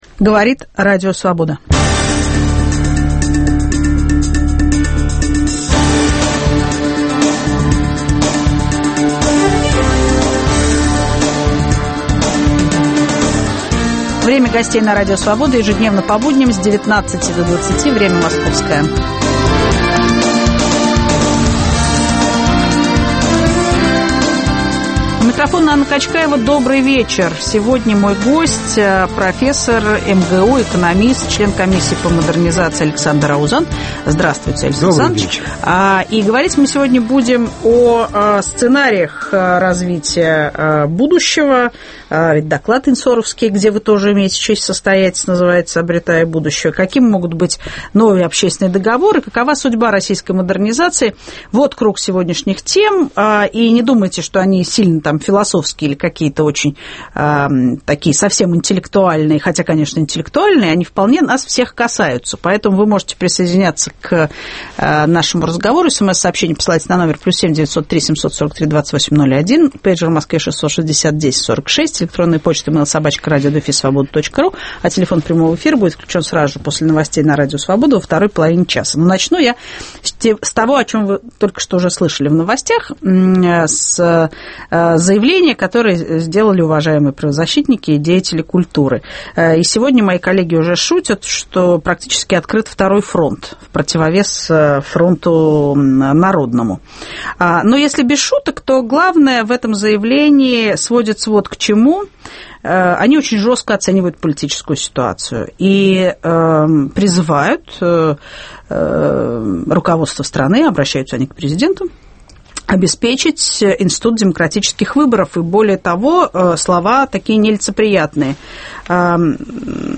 Каким может быть новый общественный договор и какова судьба российской модернизации? В студии - профессор МГУ, глава института "Общественный договор" Александр Аузан.